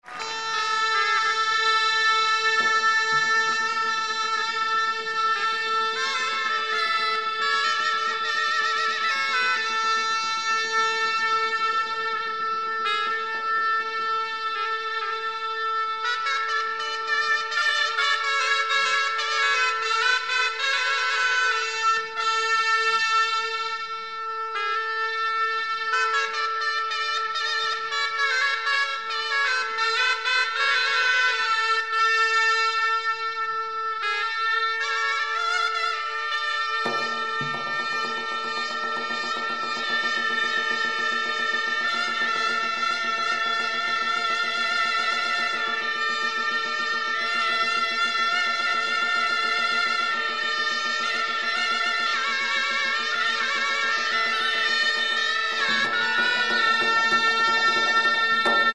Aerophones -> Reeds -> Double (oboe)